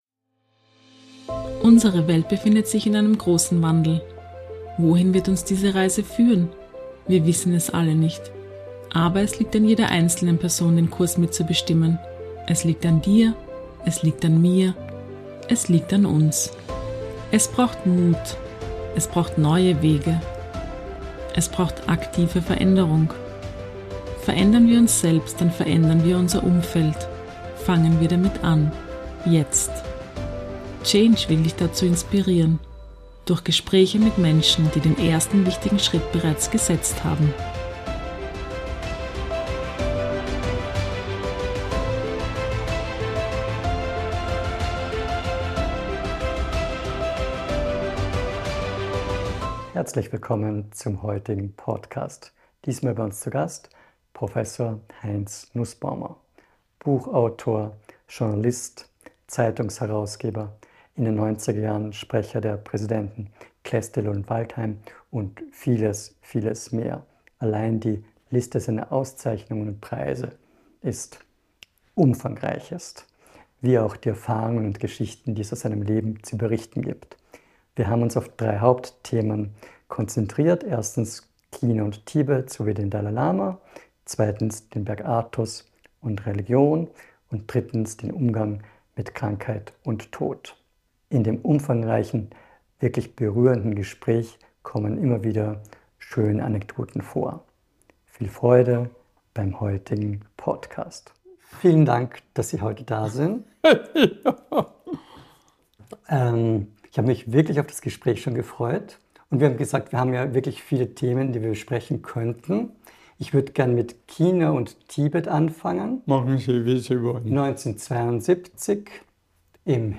Ein äußerst spannendes und vielseitiges Gespräch!